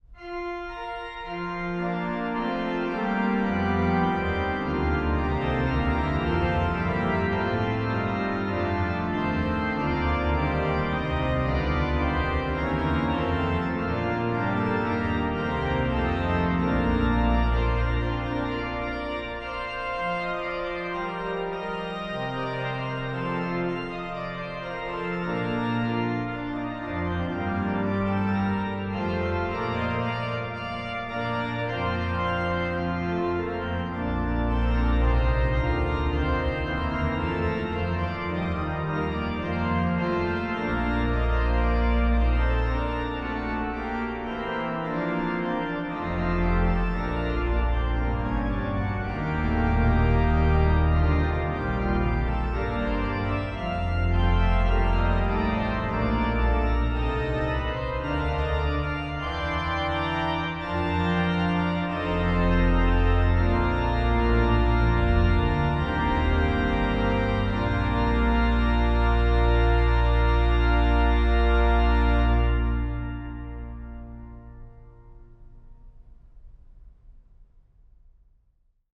Voicing: Org 3-staff